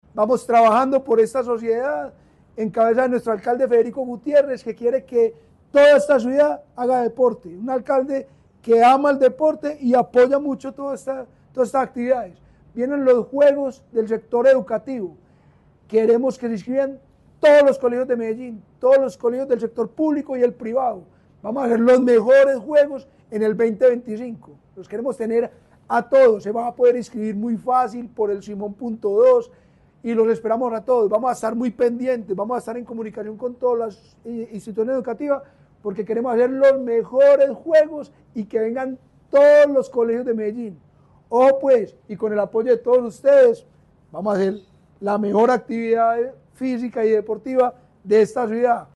Palabras de Eduardo Silva Meluk, director del Inder